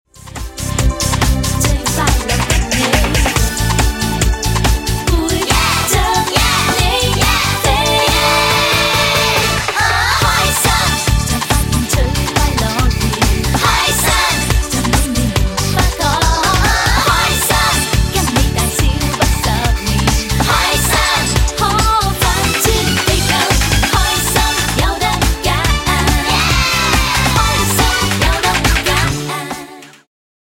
伴奏音樂